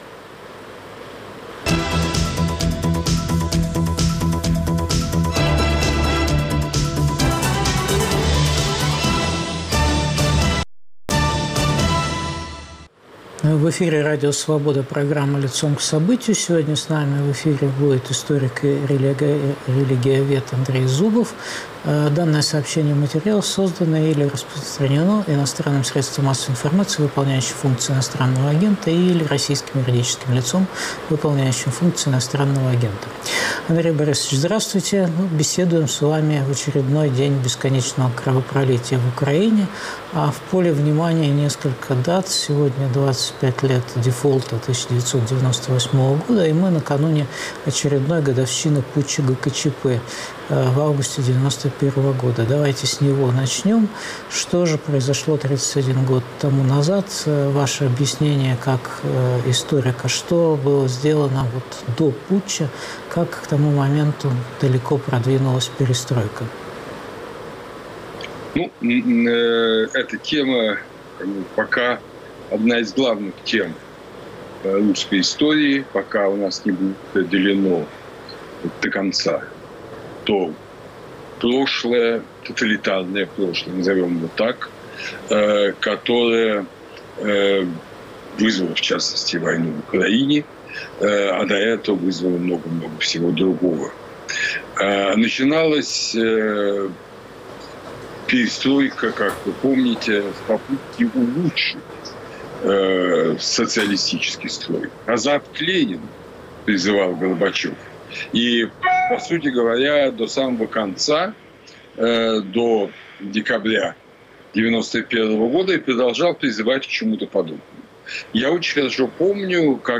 Путь к катастрофе: из 1991 в 2022 год. В эфире историк Андрей Зубов.